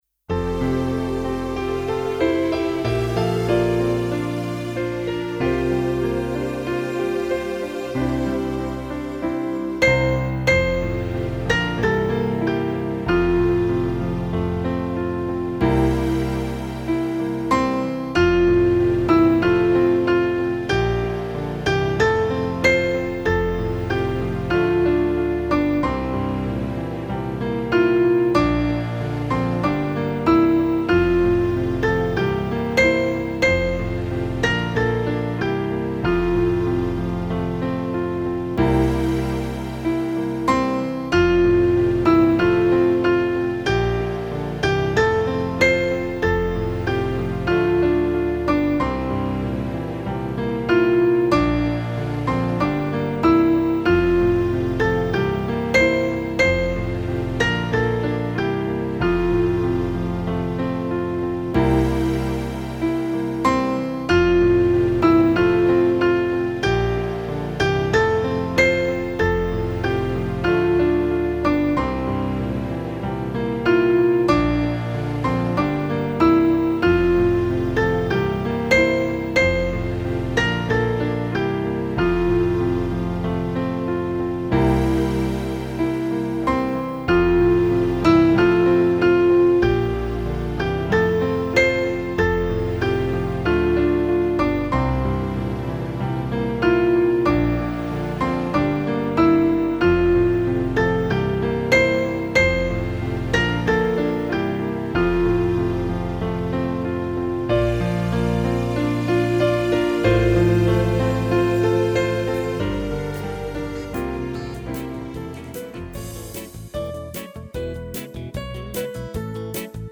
Der spilles 3 sange
Der spilles lang forspil –
Start med at synge efter 17 sekunder